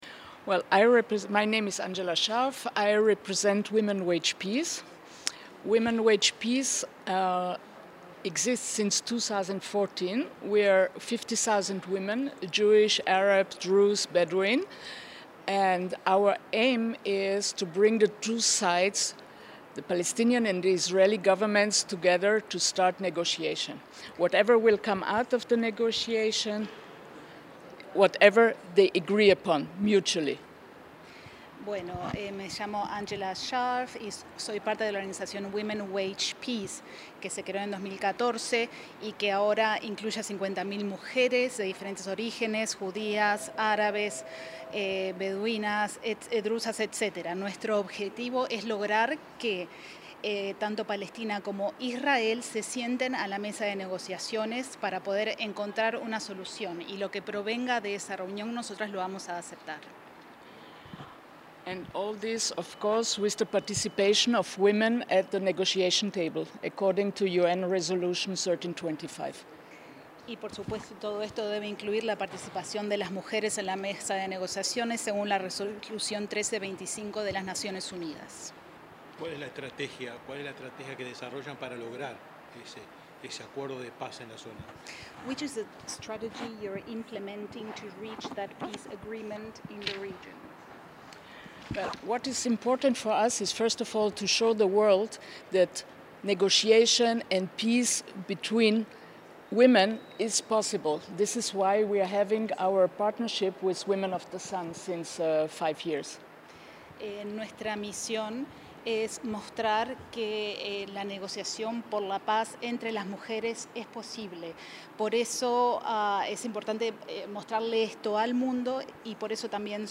Declaraciones de integrantes del colectivos internacionales por la paz